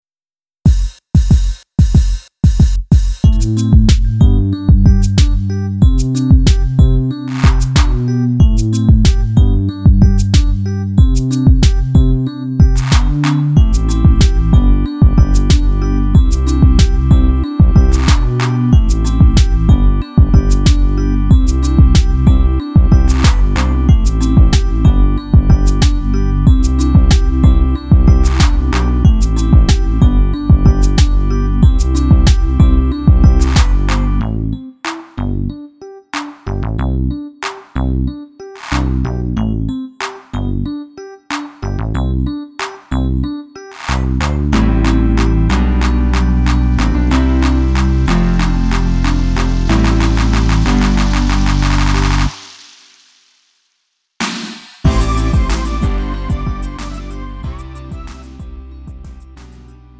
음정 원키
장르 가요 구분 Lite MR
Lite MR은 저렴한 가격에 간단한 연습이나 취미용으로 활용할 수 있는 가벼운 반주입니다.